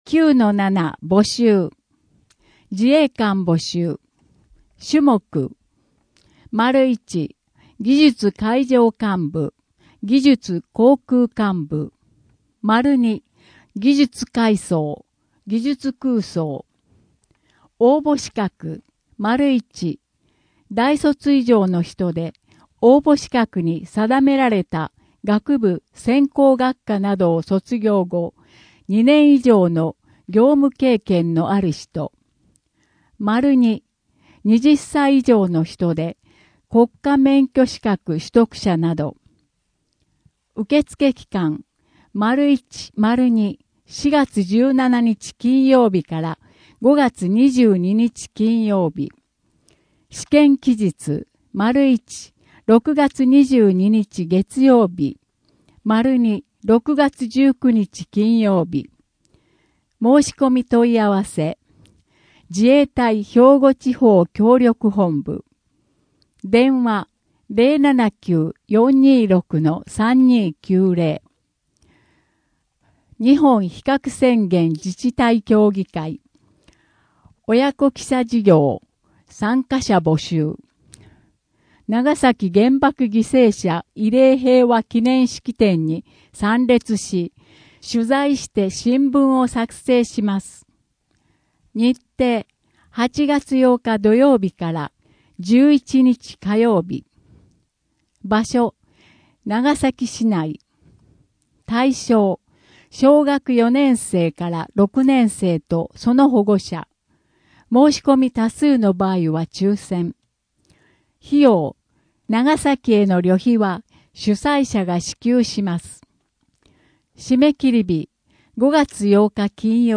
声の「広報はりま」4月号
声の「広報はりま」はボランティアグループ「のぎく」のご協力により作成されています。